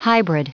Prononciation du mot hybrid en anglais (fichier audio)
Prononciation du mot : hybrid